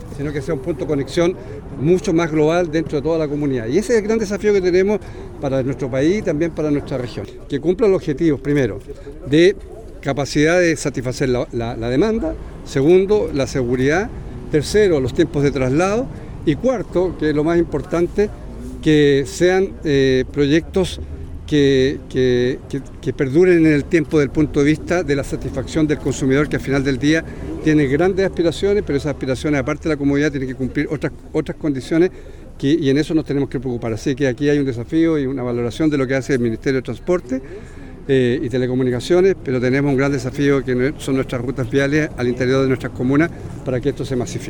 El Gobernador Regional, Alejandro Santana, destacó la importancia de este proyecto como punto de conexión en la ciudad mejorando la experiencia y calidad de vida de los habitantes de la capital regional.